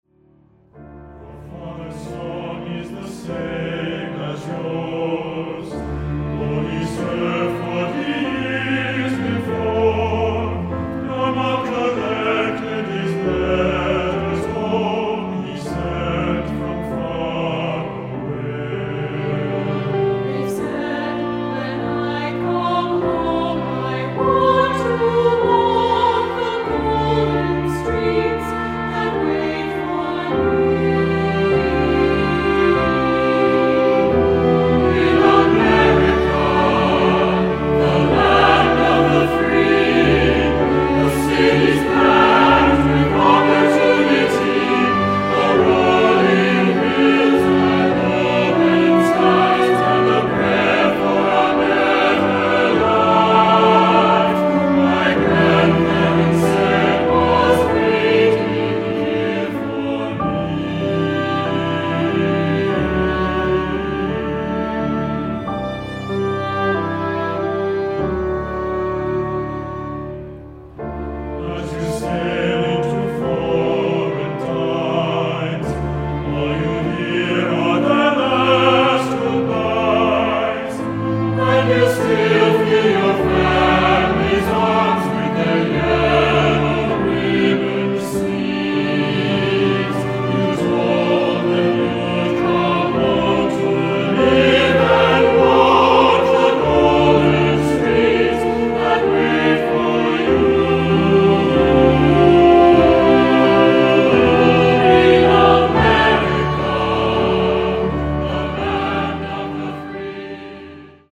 Choeur Mixte (SATB) et Piano